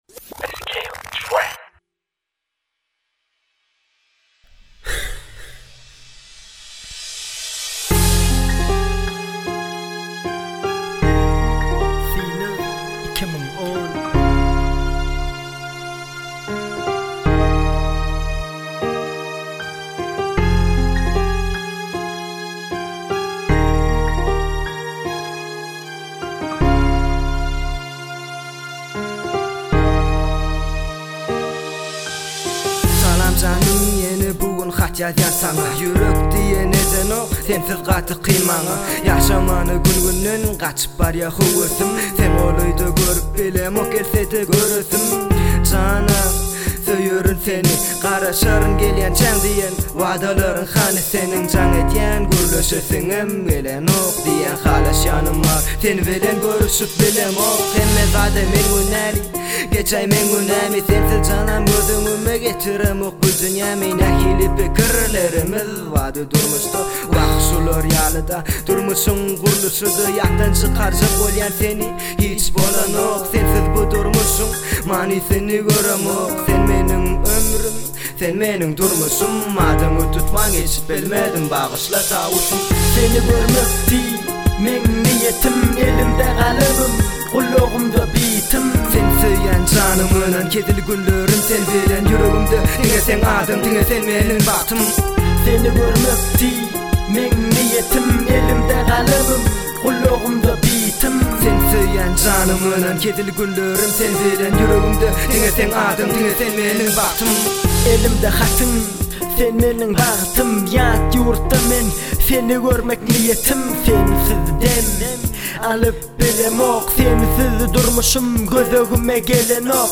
turkmen rap